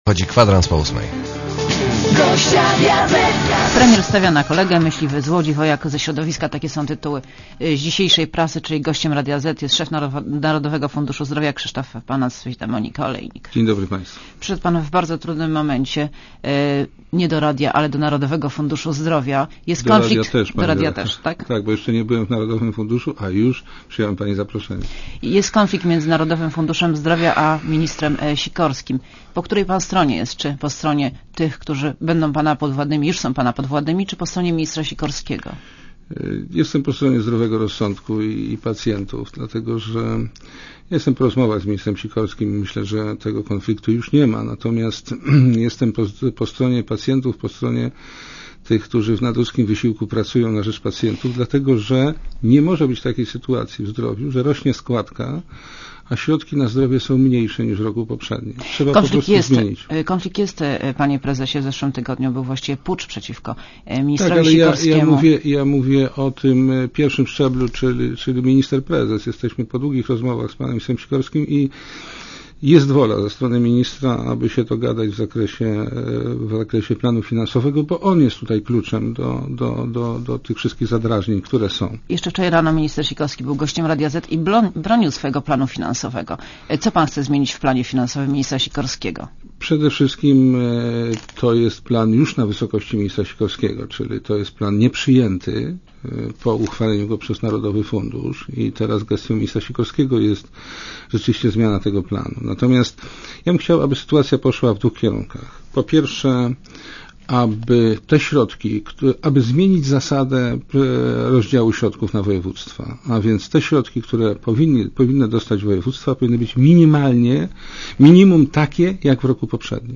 (RadioZet) Źródło: (RadioZet) Posłuchaj wywiadu (2,6 MB) „Premier stawia na kolegę”, „Myśliwy z Łodzi, „Wojak ze środowiska” – takie są tytuły w dzisiejszej prasie.